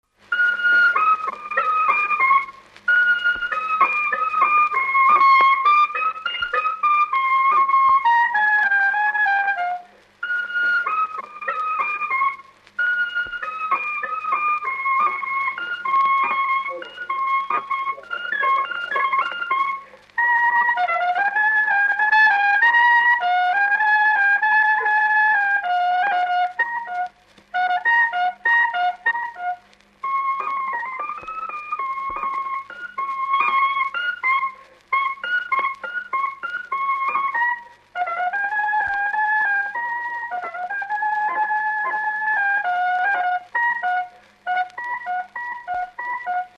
Dallampélda: Hangszeres felvétel
Hangszeres felvétel Dunántúl - Tolna vm. - Ozora Előadó
klarinét Műfaj: Dudálás Gyűjtő